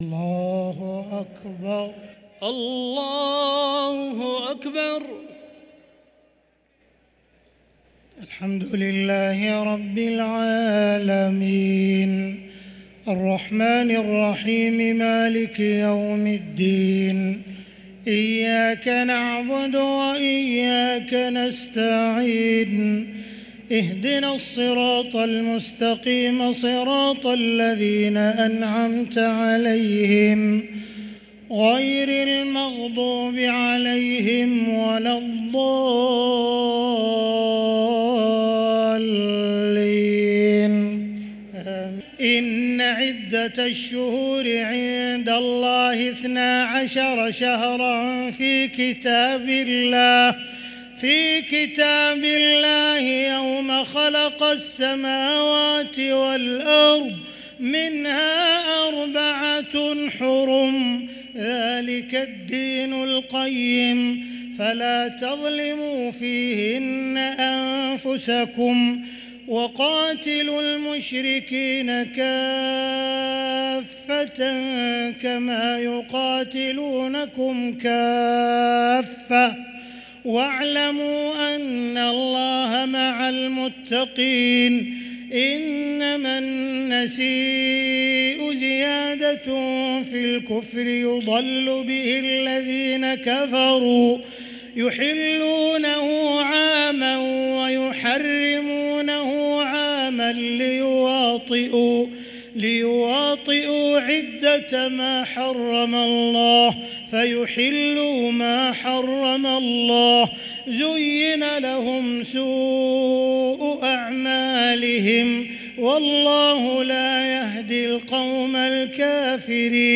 صلاة التراويح ليلة 13 رمضان 1444 للقارئ ياسر الدوسري - الثلاث التسليمات الأولى صلاة التراويح